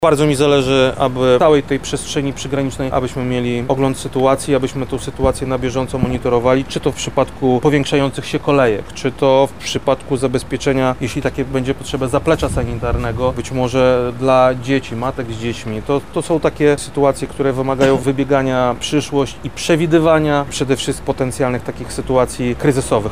– mówi wojewoda lubelski, Krzysztof Komorski.